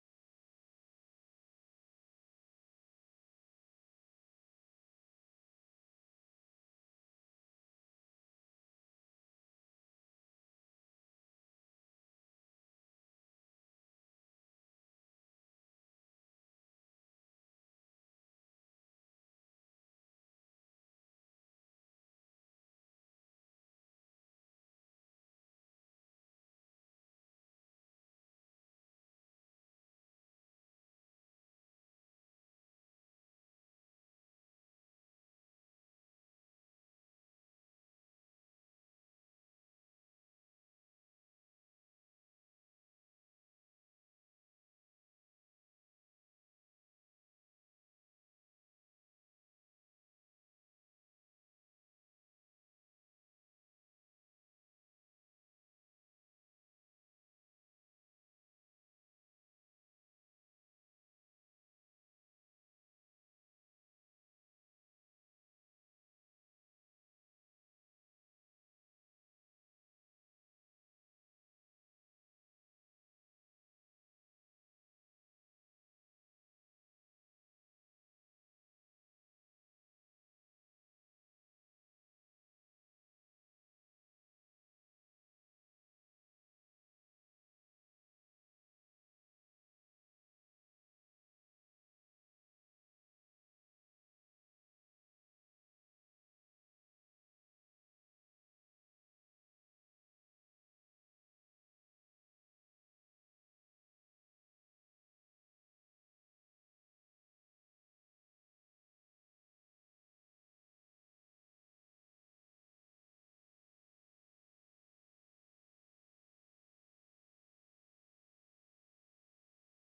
Functie: Presentator
Opgewekte muziek speelt.